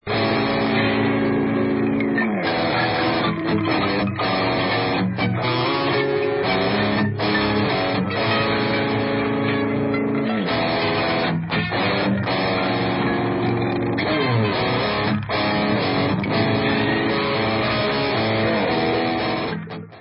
Dark, yet melodic hardcore